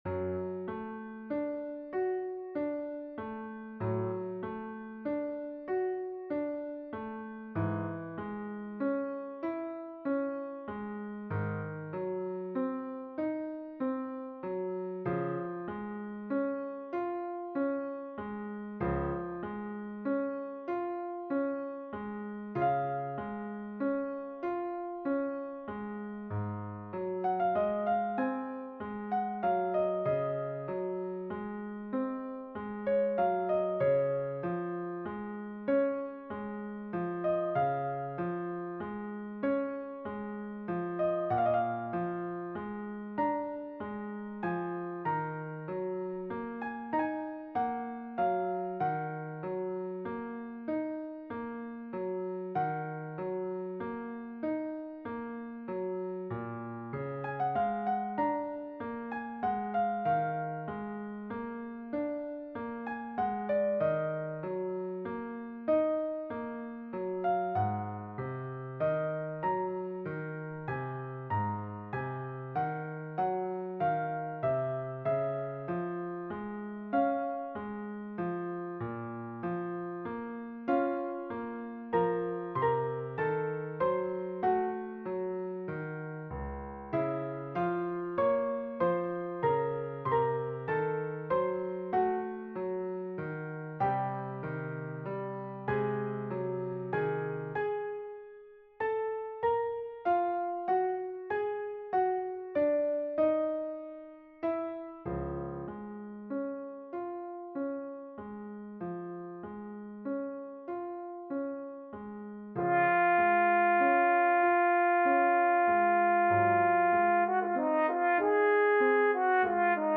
ici l’audio avec piano- (nous en avons aussi une version avec orgue)
Casta_Diva3_piano.mp3